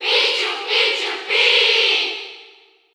Category: Crowd cheers (SSBU) You cannot overwrite this file.
Pichu_Cheer_German_SSBU.ogg